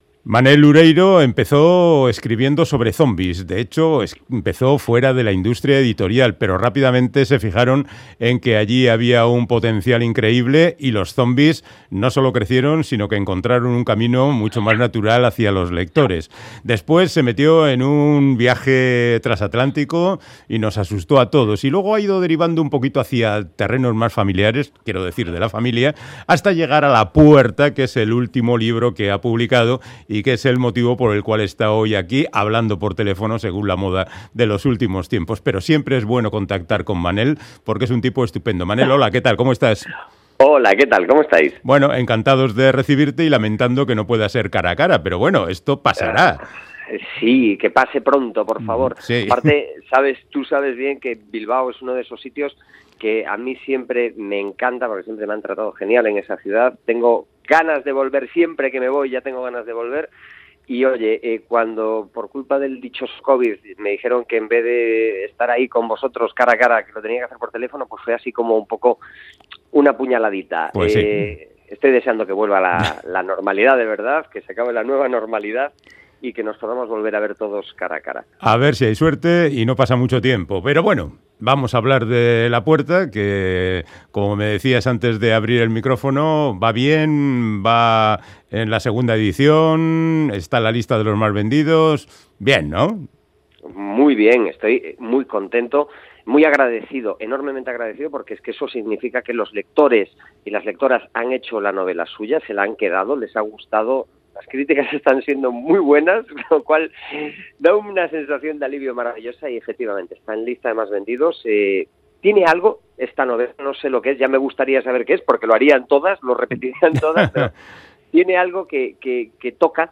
Radio Euskadi POMPAS DE PAPEL Thriller, magía y fantasía rural en Manel Loureiro Publicado: 02/11/2020 18:45 (UTC+1) Última actualización: 02/11/2020 18:45 (UTC+1) Charlamos con el escritor gallego Manel Loureiro sobre su última novela, "La puerta", donde combina ritos sangrientos, mitología fantástica y tragedias íntimas.